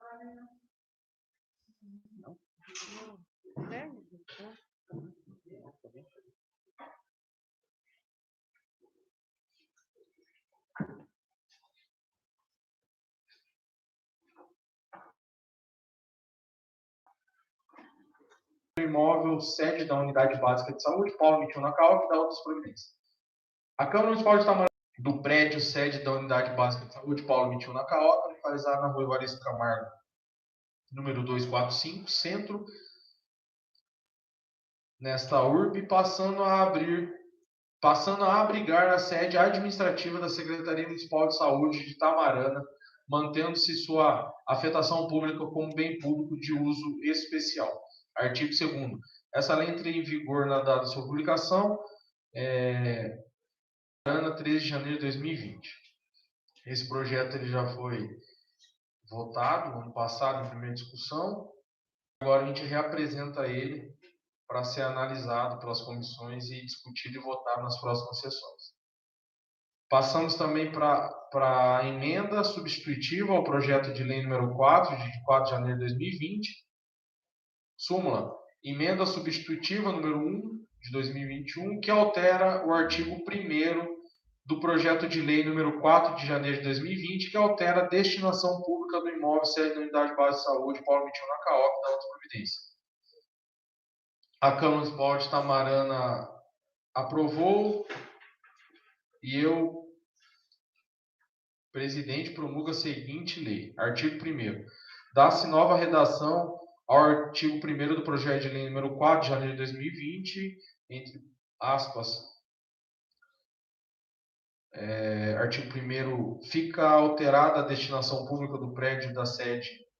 16ª Sessão Ordinária Parte 1 — Câmara Municipal de Tamarana